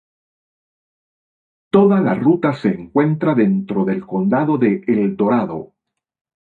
con‧da‧do
/konˈdado/